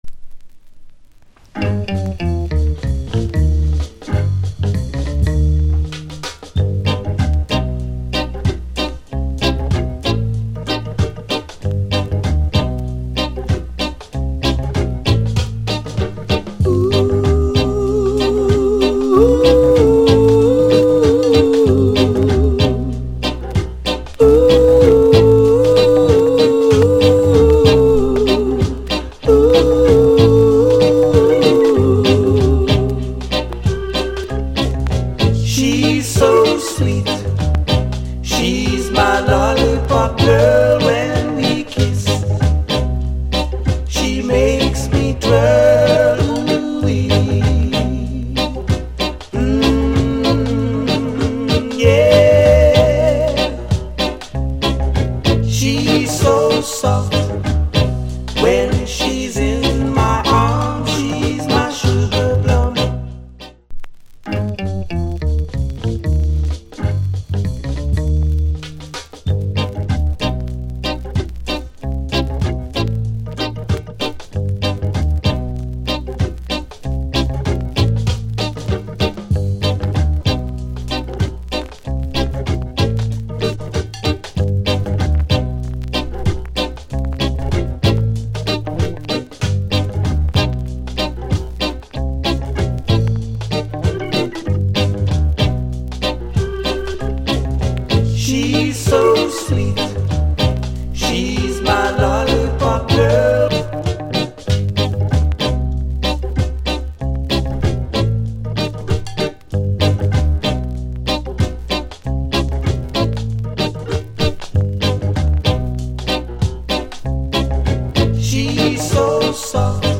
Reggae70sMid / Male Vocal Condition EX- Soundclip